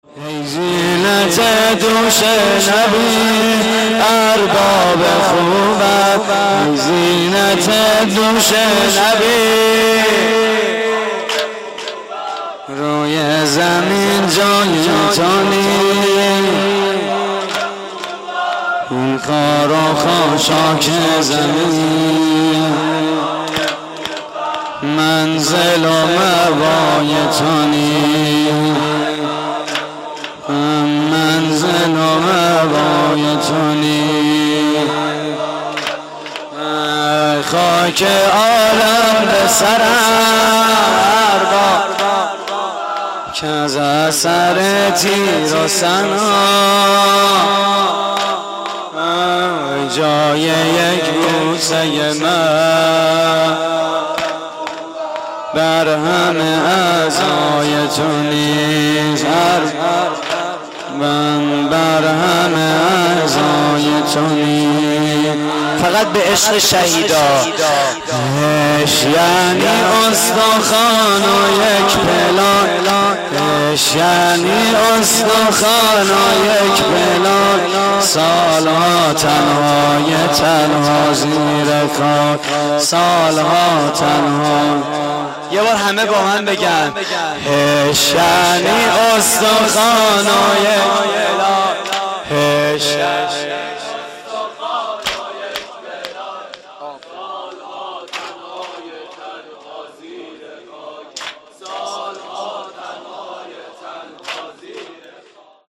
واحد: ای زینت دوش نبی
مراسم عزاداری شب عاشورای حسینی